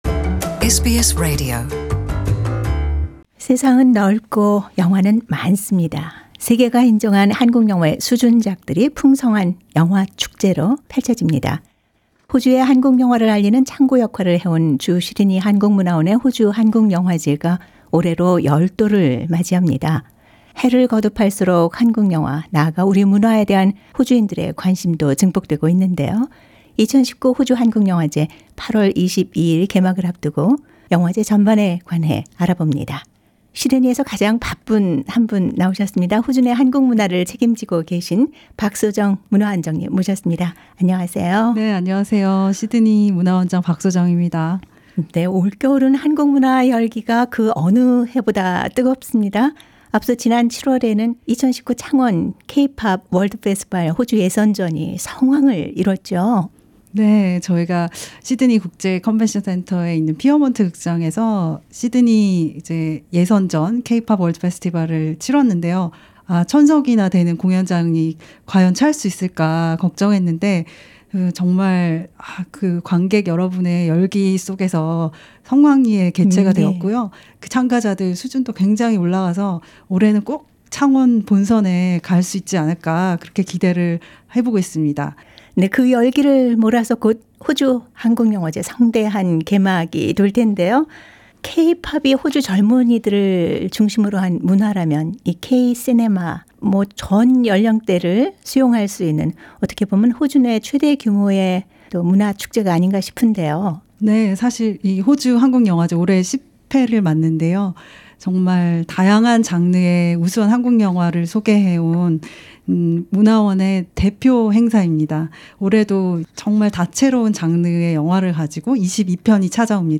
[특집대담] 2019 호주한국영화제 무엇을 담고 있나?